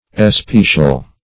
Especial \Es*pe"cial\, a. [OF. especial, F. sp['e]cial, L.